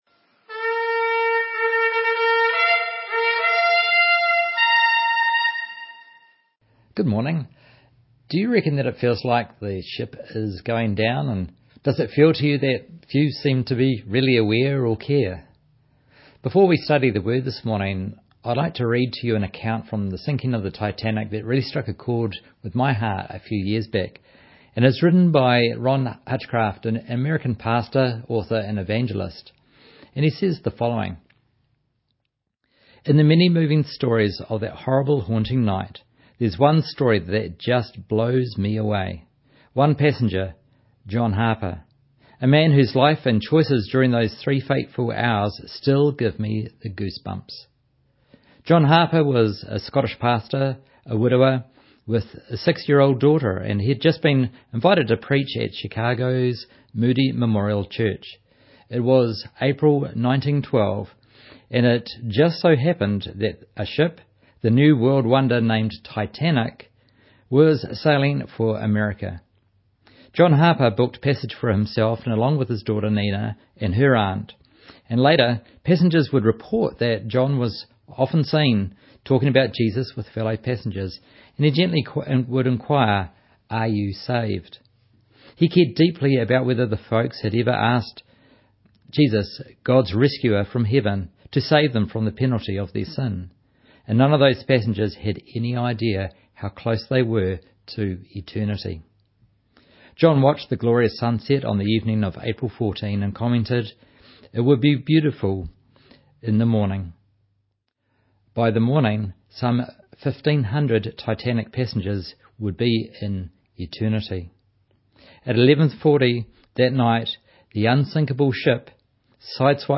Online Sunday Morning Service 16th March, 2025 Slides from this service are below.